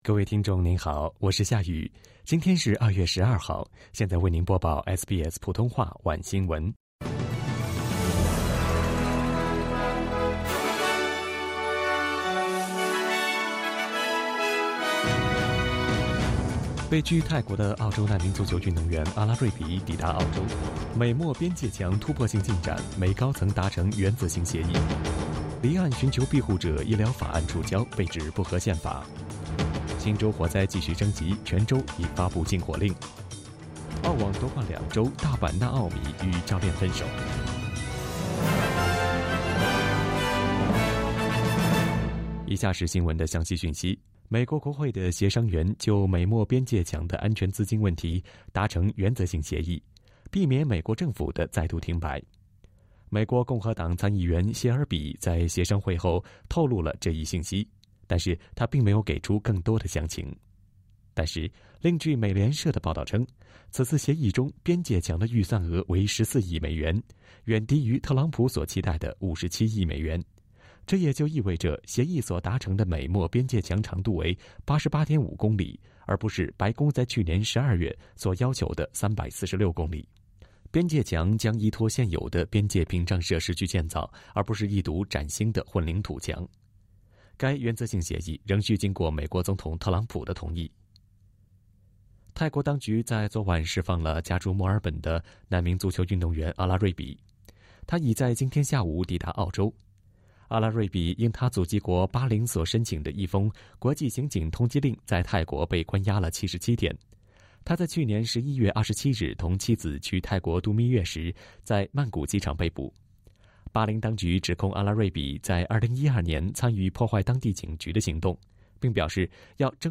SBS晚新闻（2月12日）
evening_news.mp3